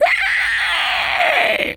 pig_scream_03.wav